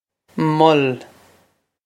Mol Muhl
This is an approximate phonetic pronunciation of the phrase.